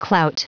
Prononciation du mot clout en anglais (fichier audio)
Prononciation du mot : clout